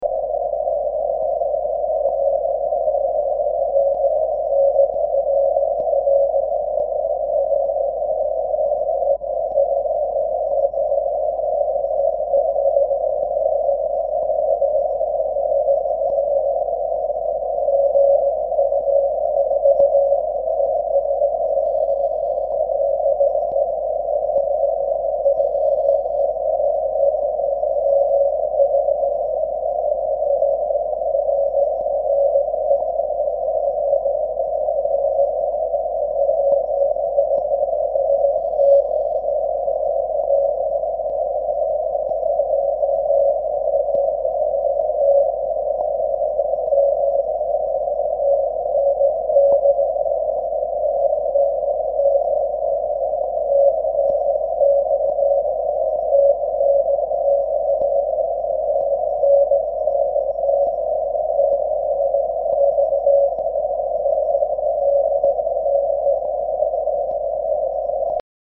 Audio sample from QSO 1 Mb in MP3 format
All super signals.. so I tried echo testing..